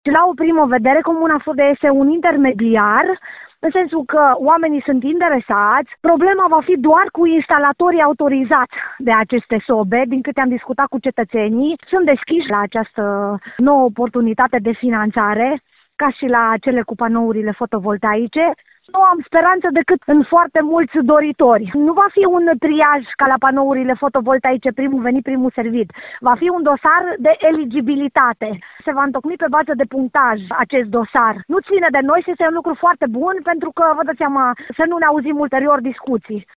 Primarul comunei Fârdea, Violeta Dobrean, spune că oamenii interesați se pot adresa primăriilor pentru a-i înscrie în program.